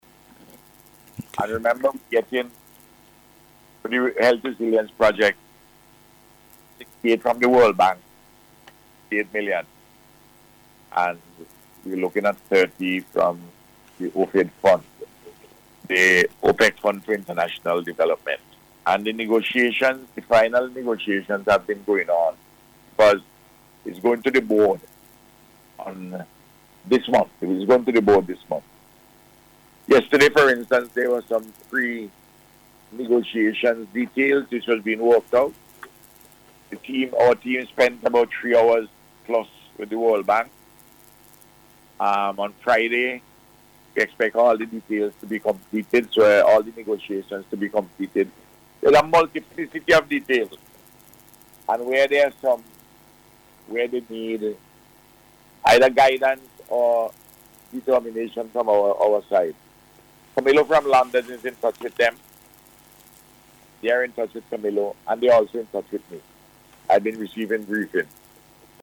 Speaking on NBC’s Face to Face programme this week, the Prime Minister said the estimated cost of the entire project is 100-million US-dollars, with the Acute Referral Hospital costing about 82-million